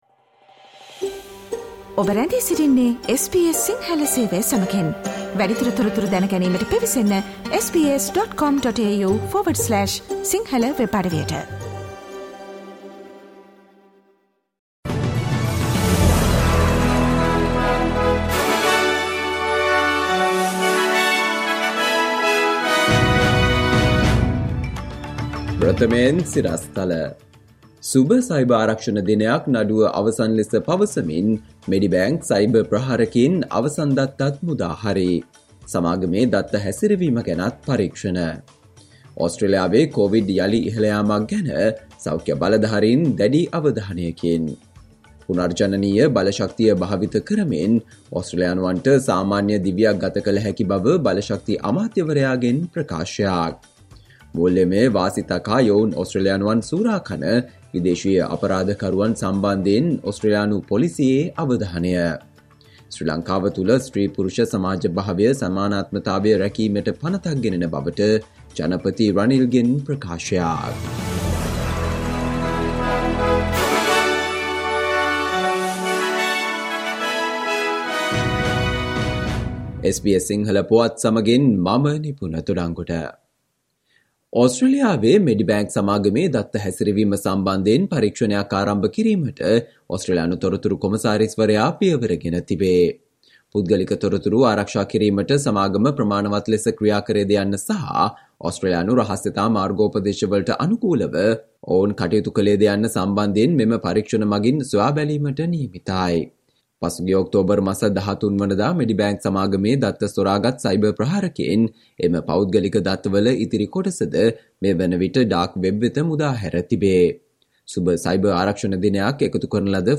Listen to the SBS Sinhala Radio news bulletin on Friday, 02 December 2022